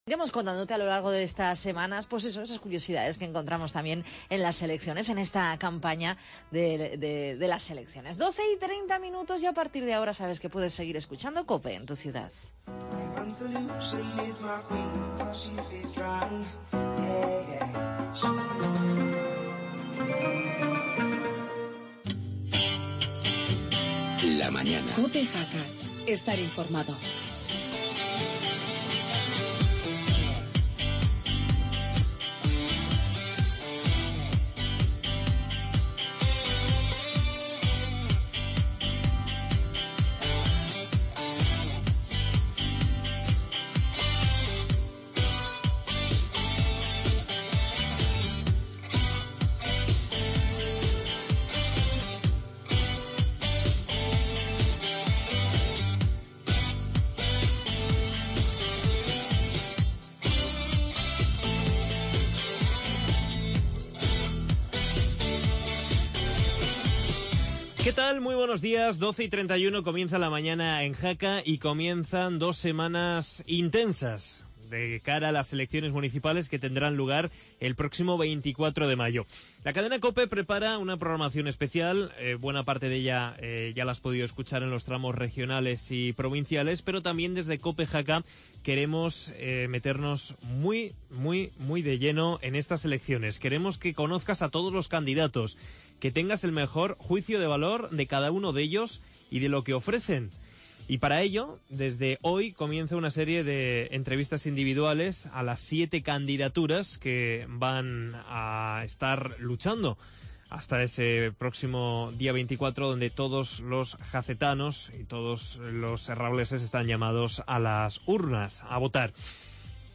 Actualidad electoral y entrevista al candidato de ARAGÓN SÍ PUEDE